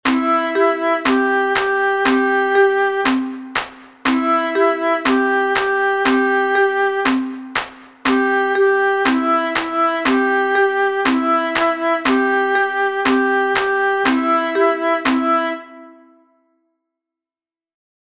Simama kaa is a Tanzanian catchy song in Swahili. It is very easy to sing and it is helpful to warm up your voice and practise properly breathing and vocalisation.